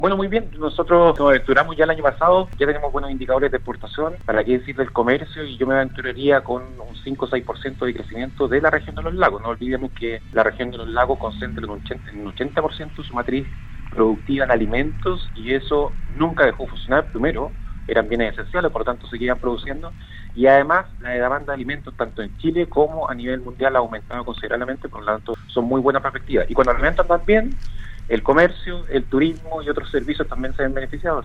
En entrevista con Radio Sago, el seremi de Economía, Francisco Muñoz, comentó las proyecciones económicas en la coyuntura en la que se discute un cuarto retiro de fondos previsionales, se proyecta el retiro de utilidades de las grandes empresas con cifras históricas, lo que aumentaría la inflación.